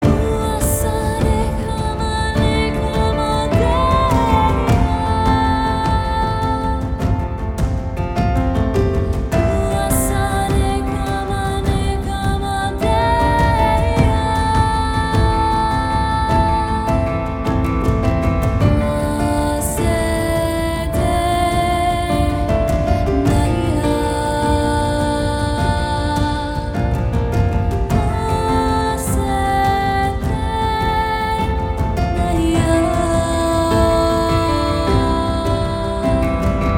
спокойные
Просто спокойная мелодия